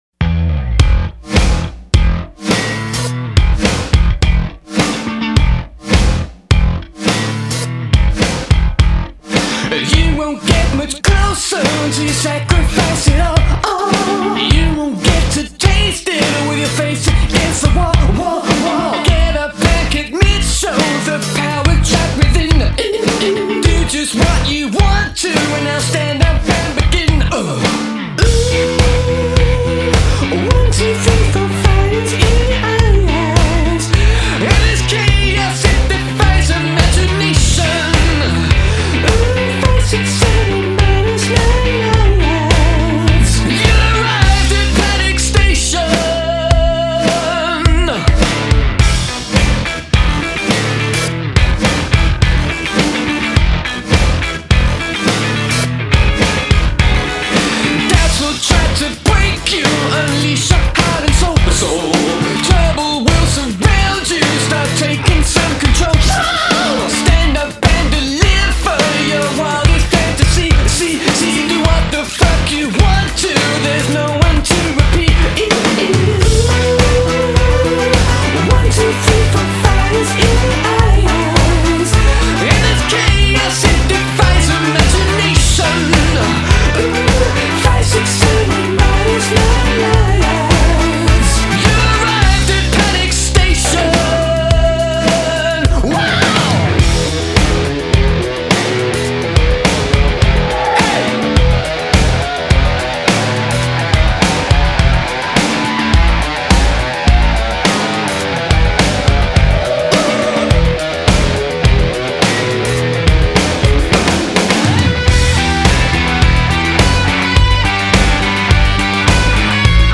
big polished RAWK
Great simple beat.